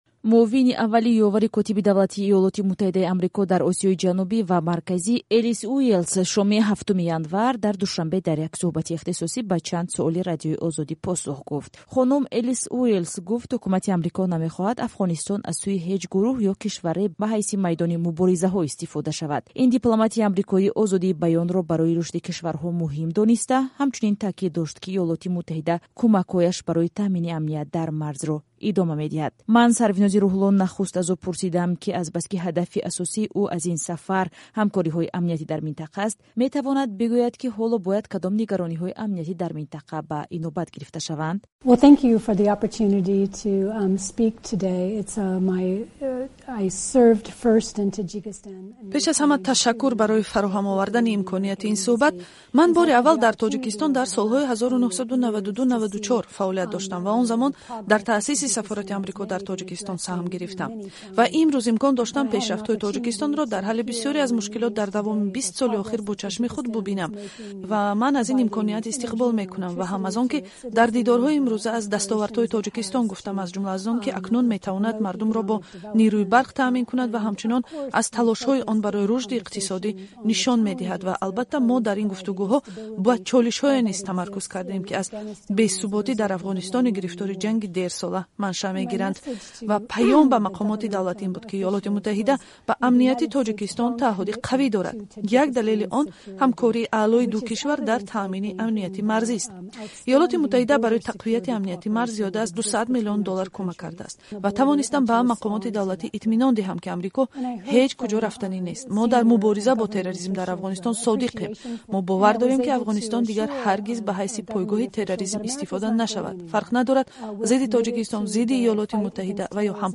Мусоҳиба бо ноиби ёвари Помпео, ки ба Тоҷикистон омадааст